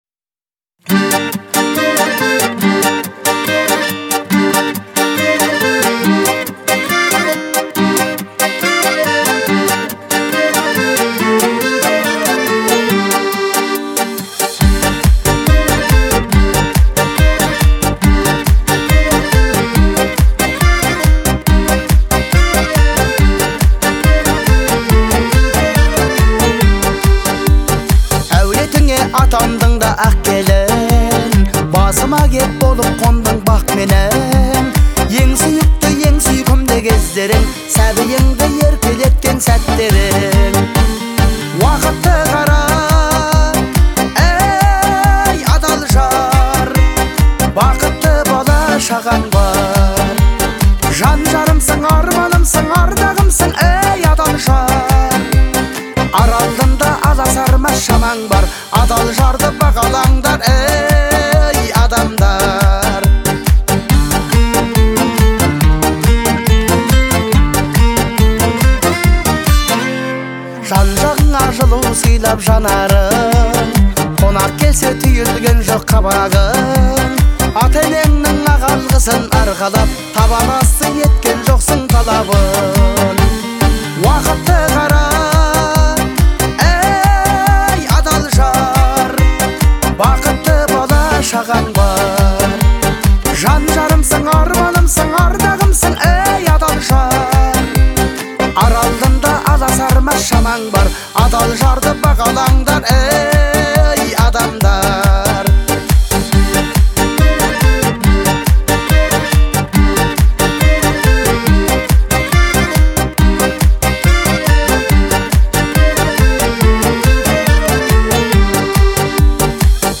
сочетая традиционные мелодии с современными аранжировками.